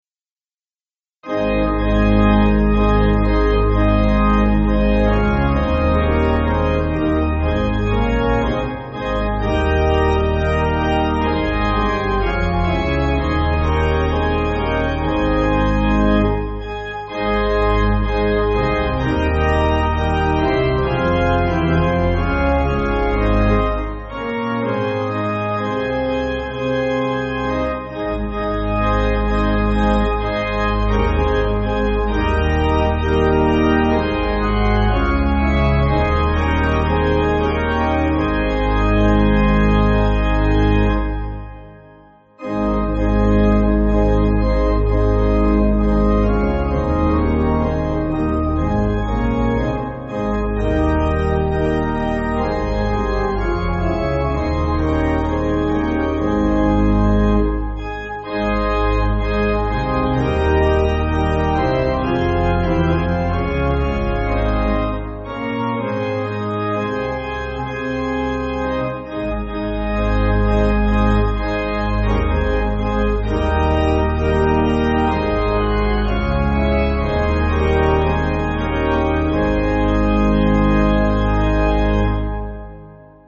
Organ
Slower